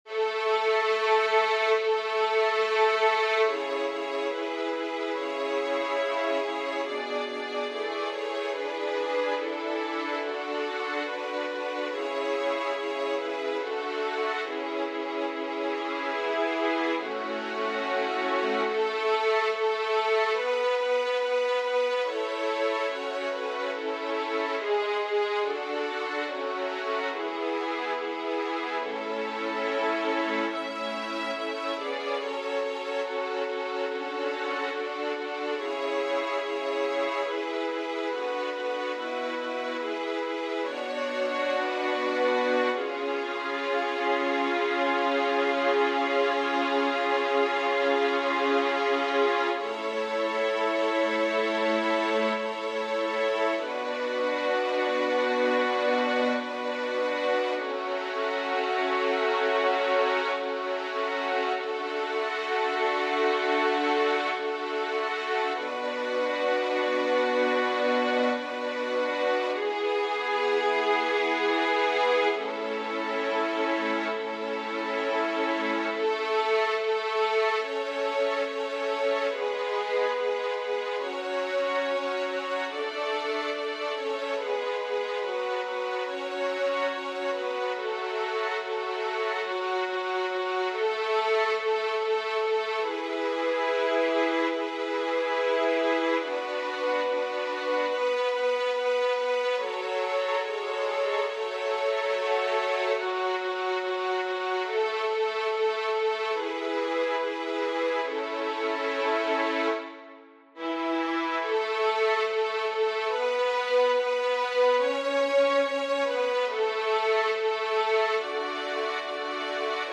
• Catégorie : Les Hymnes